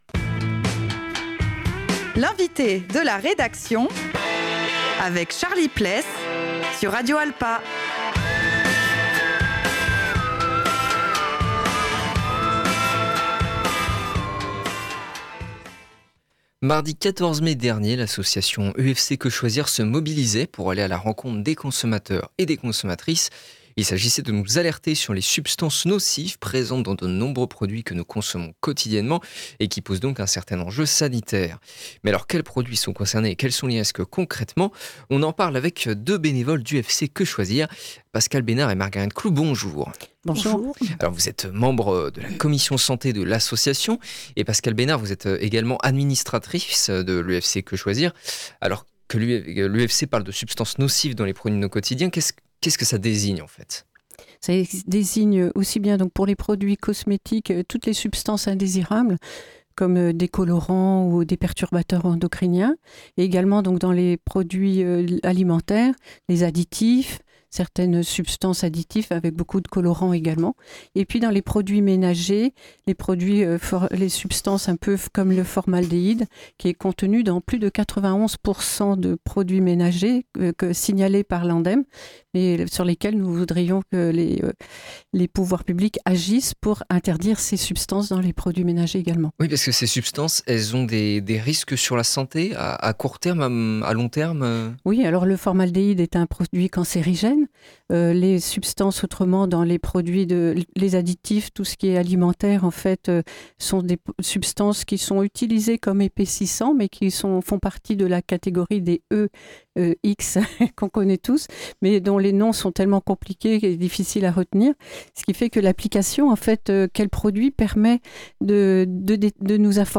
On en parle avec deux bénévoles d’UFC que choisir